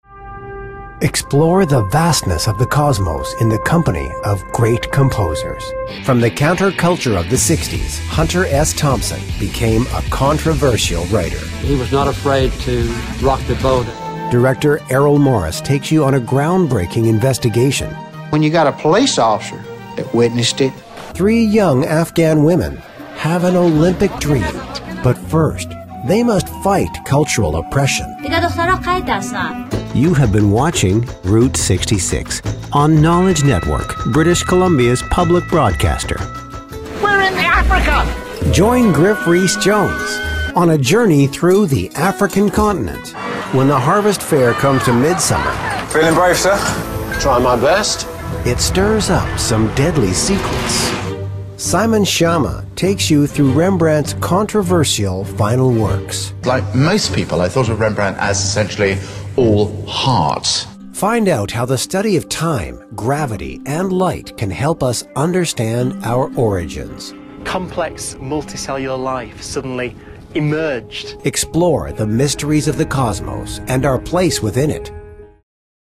TV Promo